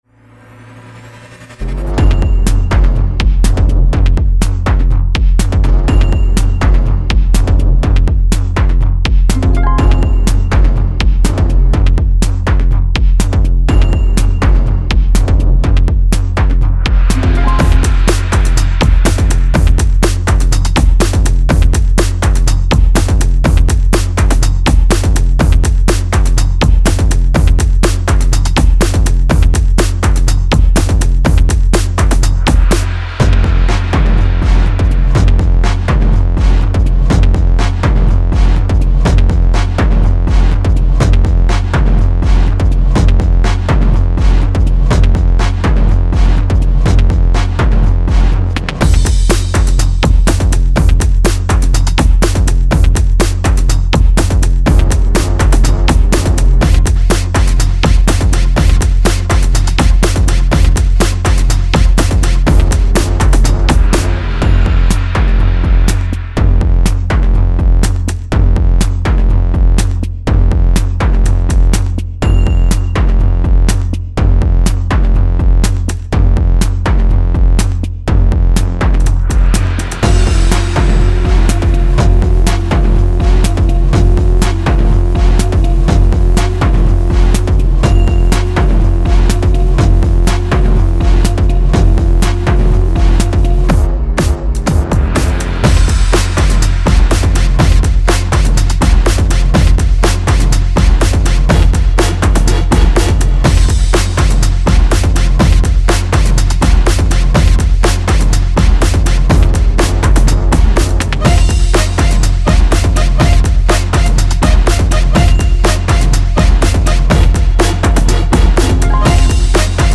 Немного треша с басами и перкуссиией )) [PreRELEASE]